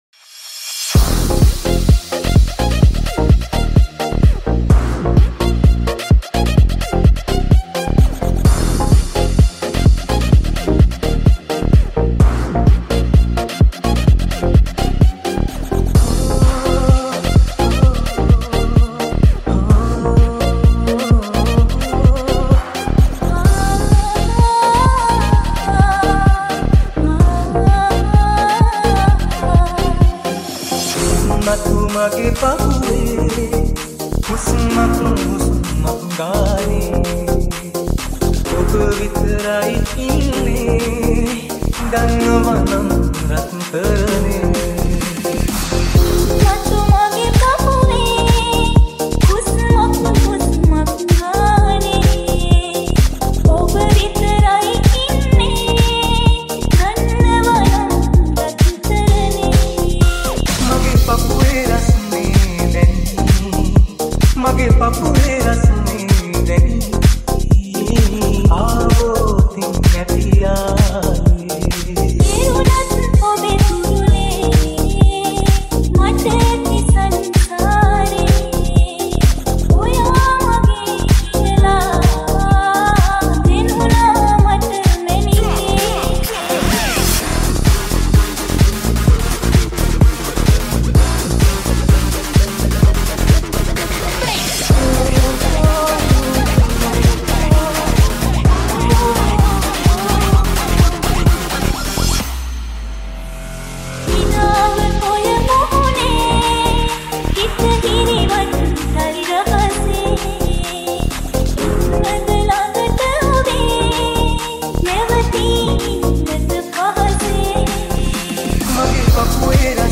Sawanatha Remix New Song
Remix song